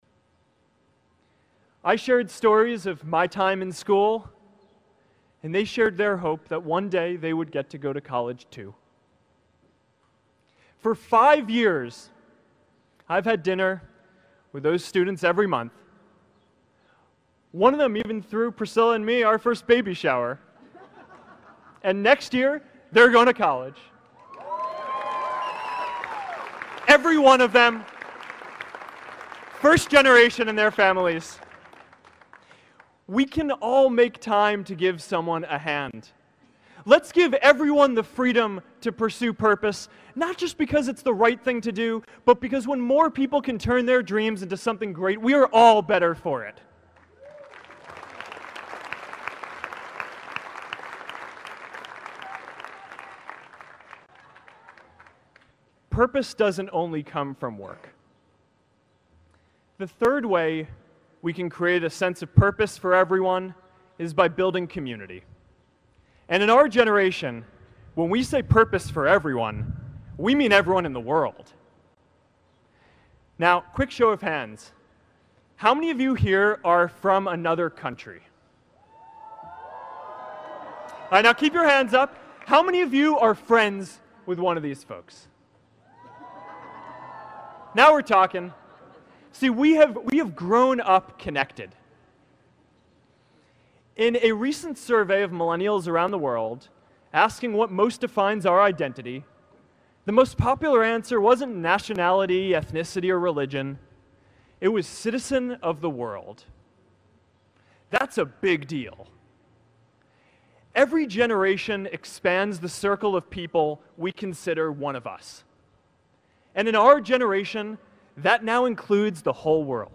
公众人物毕业演讲 第467期:扎克伯格2017哈佛毕业演讲(11) 听力文件下载—在线英语听力室